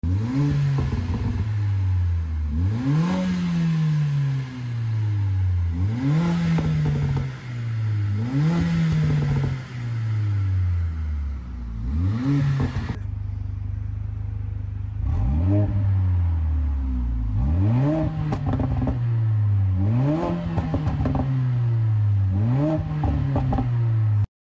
Gla 45 stock exhaust sound sound effects free download
Gla 45 stock exhaust sound a few pops and bangs second clip sounds much better🌫